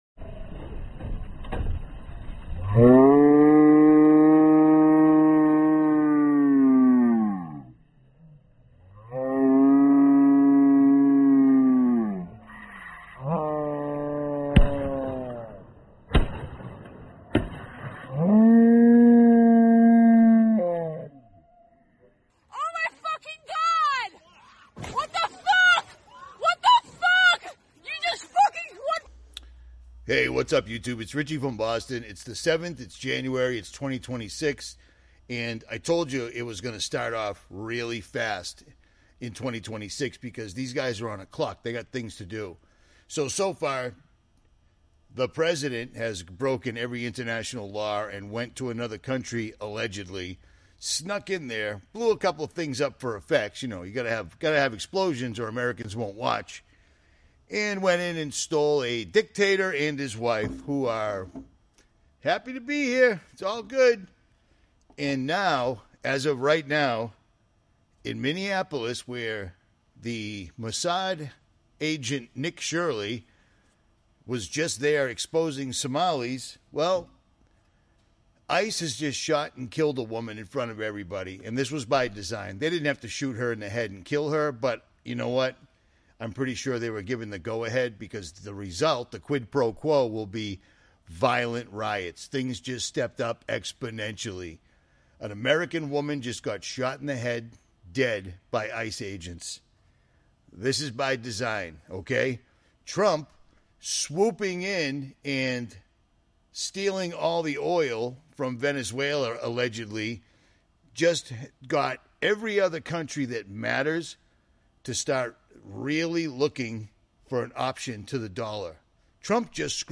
The speaker talks about big changes happening around the world and in our country. He mentions the President's actions, a serious incident in Minneapolis, and new robots taking jobs. He also worries about the US dollar losing its power, which could make things more expensive.